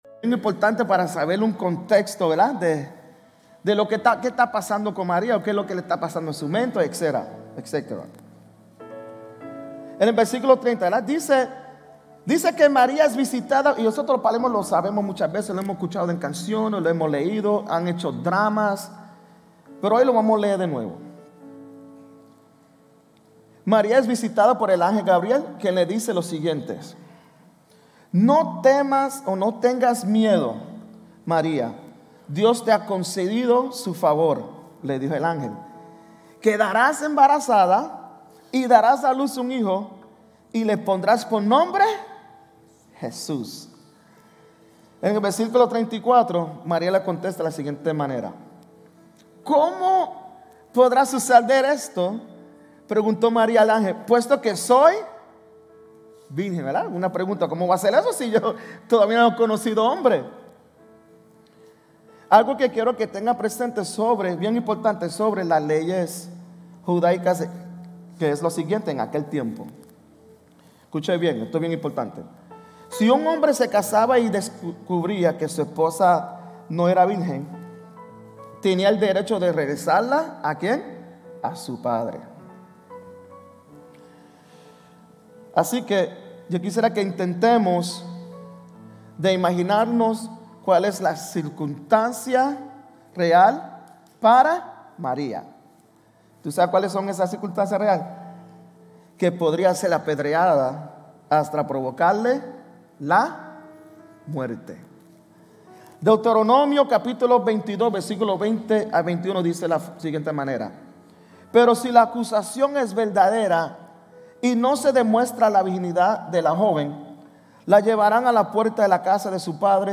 GCC-GE-December-3-Sermon.mp3